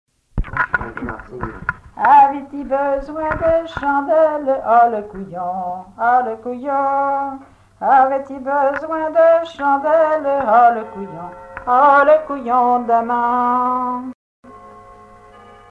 Genre brève
Chansons traditionnelles et populaires
Pièce musicale inédite